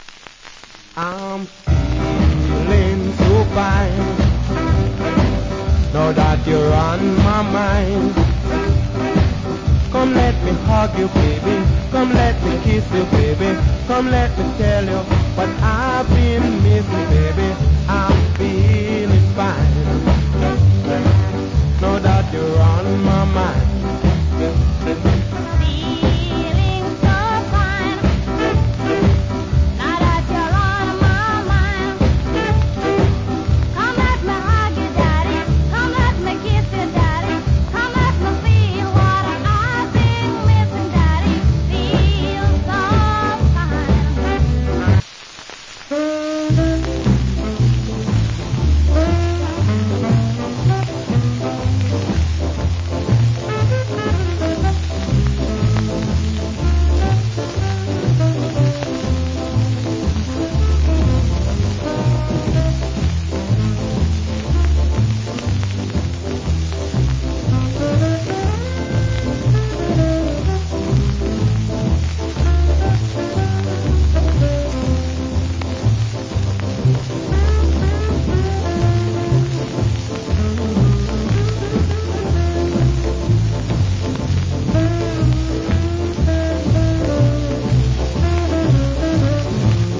Nice Duet Jamaican R&B Vocal.